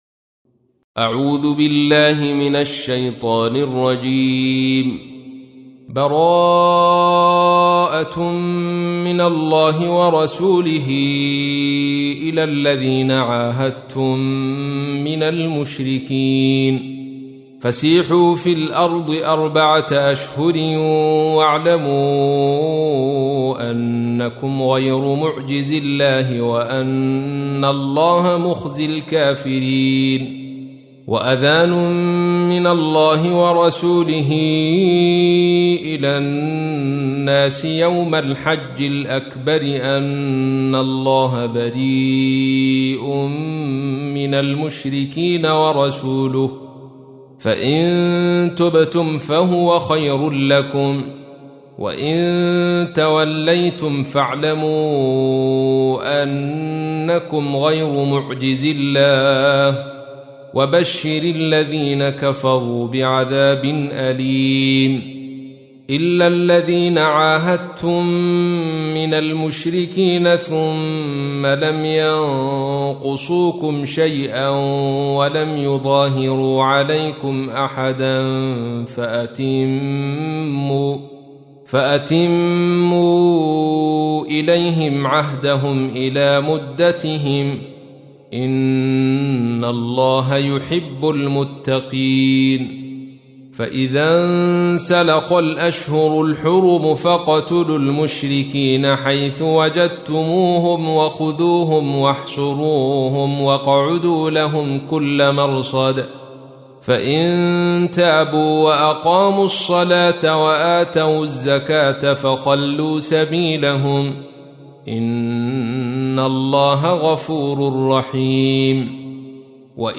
تحميل : 9. سورة التوبة / القارئ عبد الرشيد صوفي / القرآن الكريم / موقع يا حسين